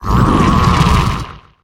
Cri de Wagomine dans Pokémon HOME.